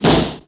1 channel
pop.wav